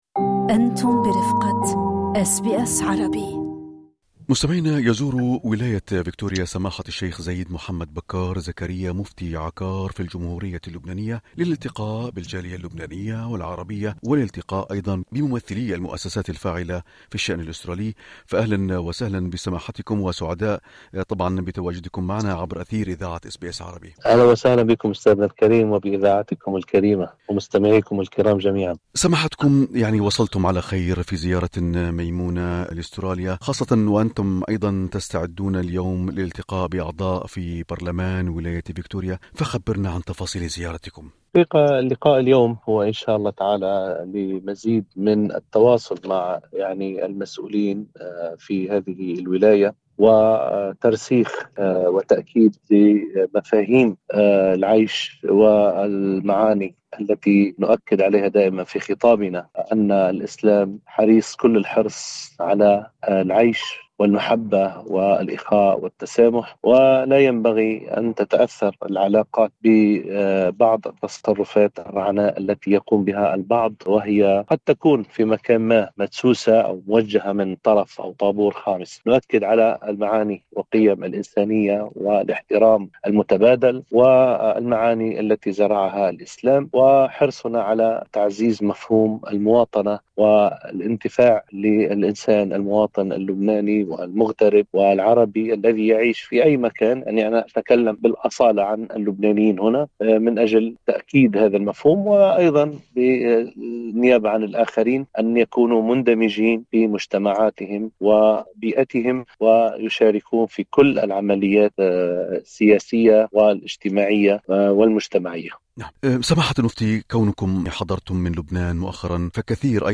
ولكن ماذا عن رأيه في أحداث بونداي؟ والعنف والتطرف؟ وخطورة منصات الكراهية؟ ودور الجالية المسلمة في أستراليا؟ استمعوا لإجابة سماحة المفتي، بالضغط على زر الصوت في الأعلى.